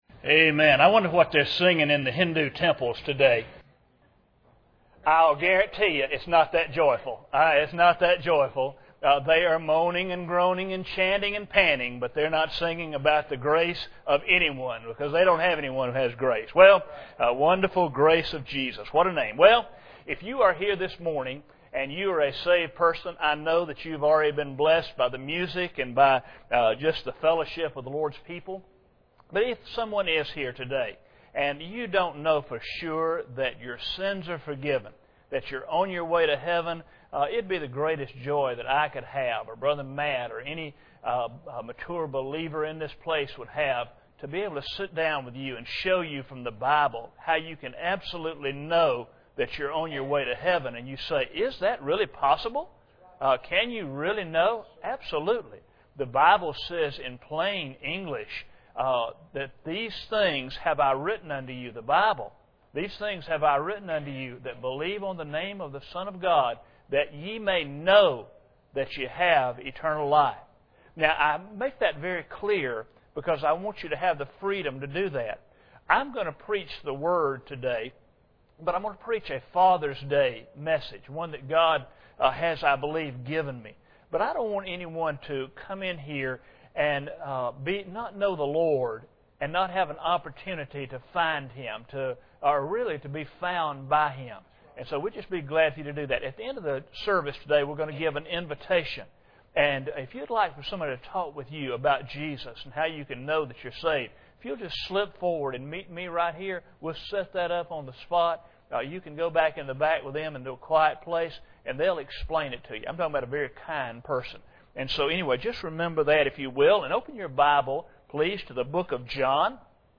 John 5:19-23 Service Type: Sunday Morning Bible Text